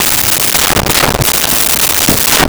Kitchen Drawer Open Close
Kitchen Drawer Open Close.wav